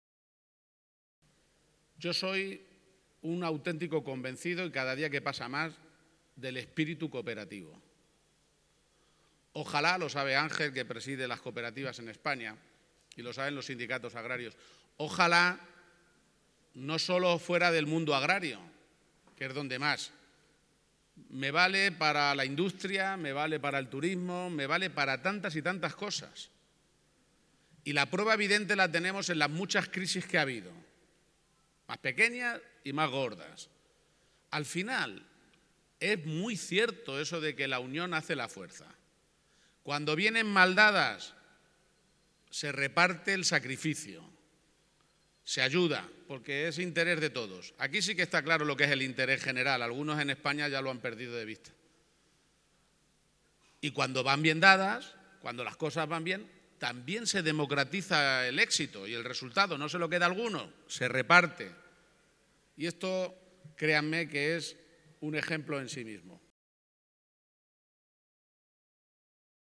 El presidente de Castilla-La Mancha, Emiliano García-Page, ha elogiado este mediodía en Quintanar del Rey, en la provincia de Cuenca, el “espíritu cooperativo” del que ha reconocido ser “un auténtico convencido”. A ello, ha añadido que ojalá esto no fuera solo en el mundo agrario, sino en otros ámbitos y ha destacado que, con las crisis se ha puesto de manifiesto que la unión hace la fuerza, ha reflexionado en la celebración del 75 aniversario de la cooperativa San Isidro.